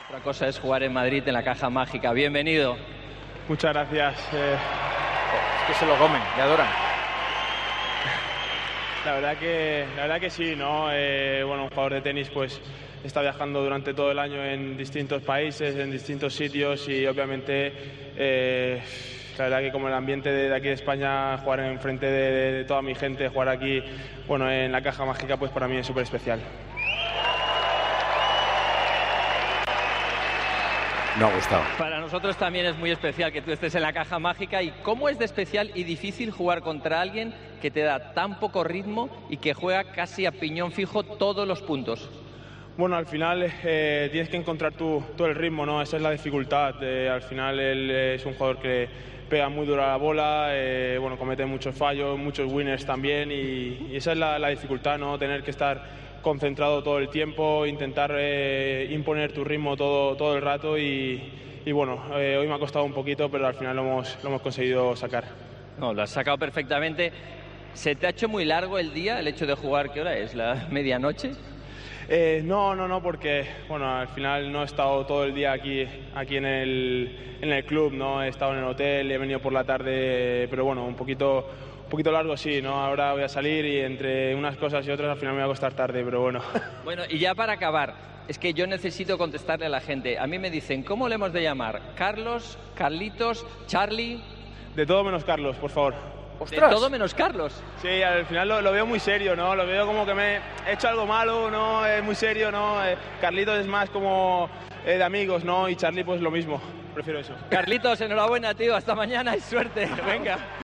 En la entrevista a pie de pista que Carlos Alcaraz realizó para RTVE, en el micrófono de Álex Corretja, contó que prefiere que le llamen 'Charly' o 'Carlitos'.
"Carlos lo veo muy serio, es como que he hecho algo malo. Carlitos es más como de amigos, y Charly, igual", declaró desatando las carcajadas del público de la pista central de la Caja Mágica, en Madrid.